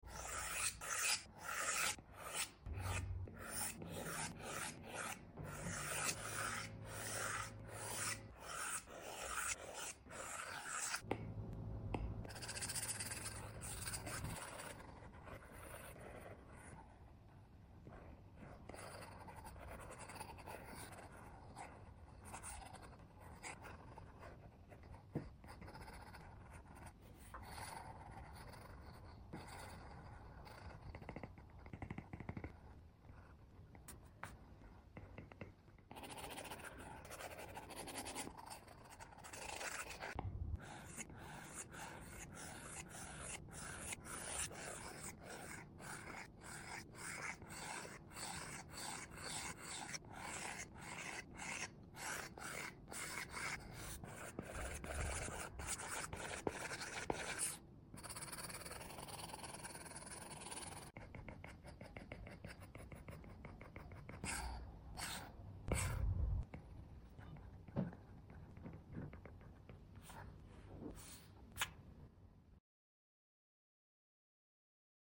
Speed Painting ASMR Sound Effects Free Download